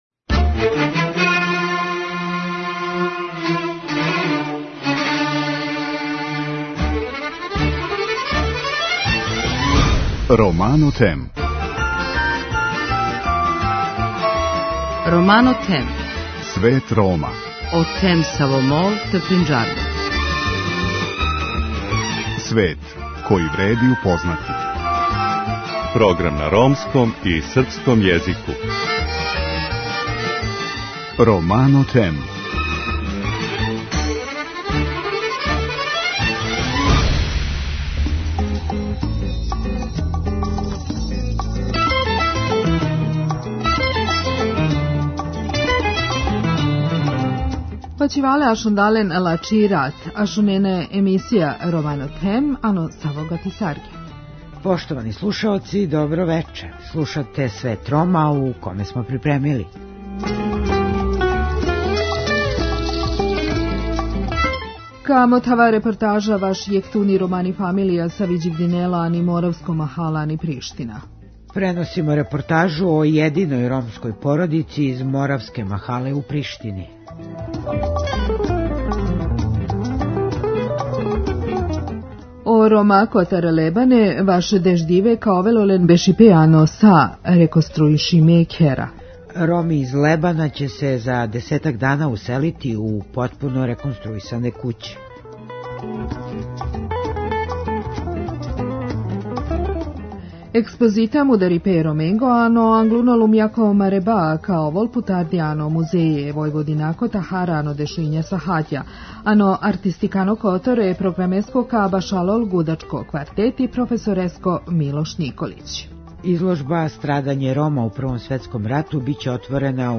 Преносимо репортажу о јединој ромској породици из Моравске махале у Приштини.